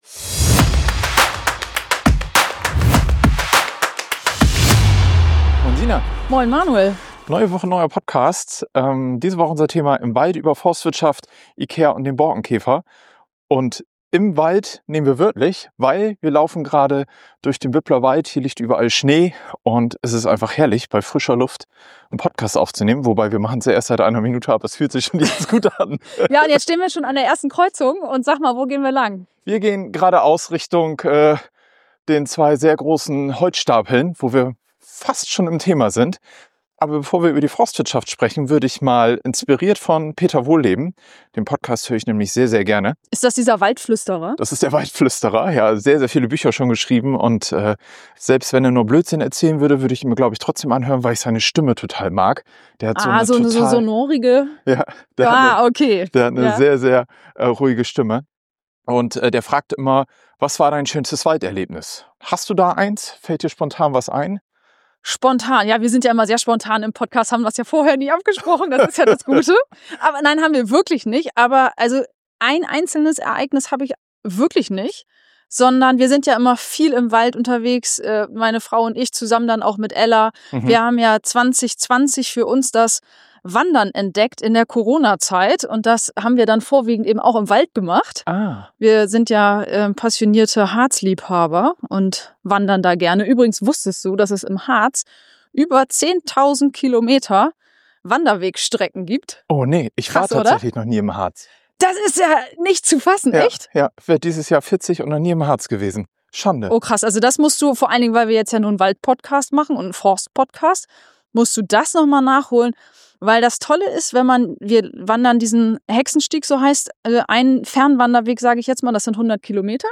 Unser Format ist locker, aber informativ, ernsthaft, aber nicht kompliziert – perfekt für alle, die Politik nicht nur hören, sondern auch mitdenken und mitdiskutieren wollen.